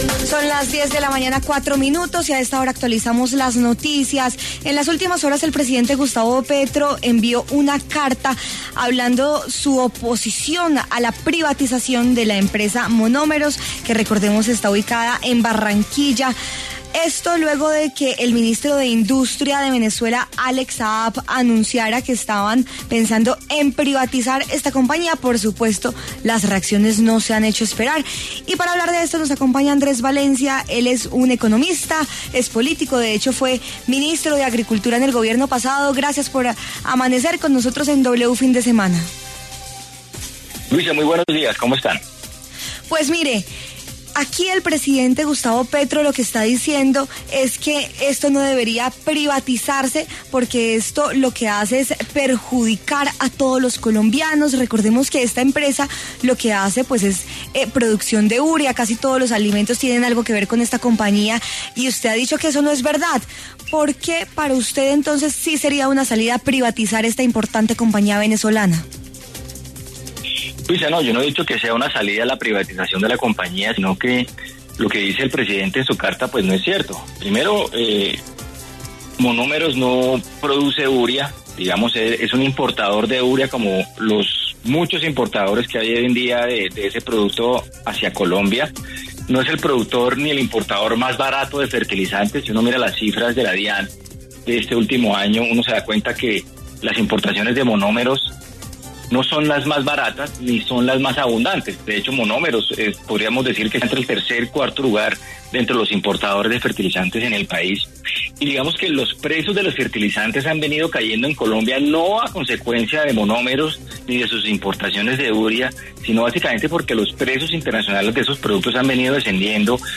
Ante ese panorama, en W Fin De Semana habló el exministro de Agricultura Andrés Valencia, quien aseguró que lo dicho por el mandatario de los colombianos “no es cierto”.